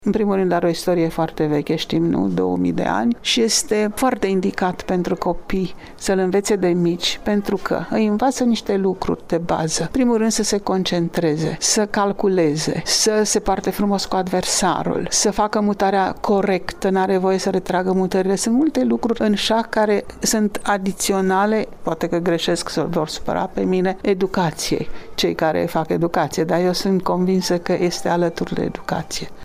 într-un interviu acordat colegei noastre